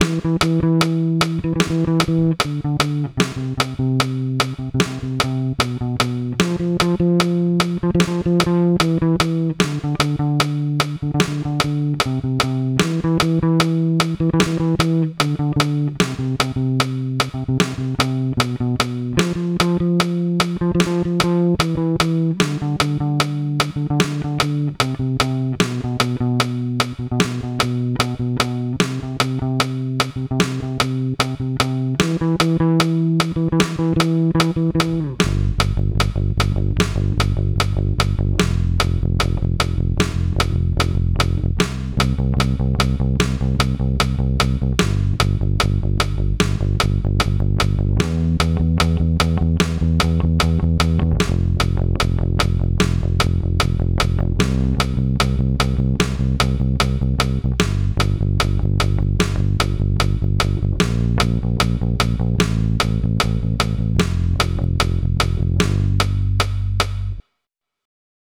Dwa kawałeczki nagrywane na szybko na line6 u kumpla.